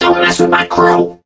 mech_crow_start_03.ogg